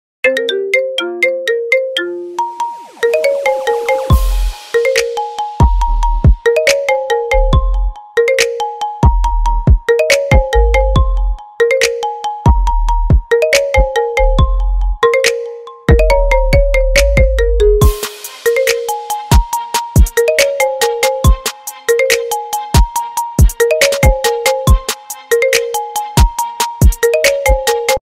Kategori Marimba